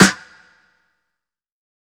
• '00s Ambient Steel Snare Drum G Key 05.wav
Royality free acoustic snare sound tuned to the G note. Loudest frequency: 2282Hz
00s-ambient-steel-snare-drum-g-key-05-Ba9.wav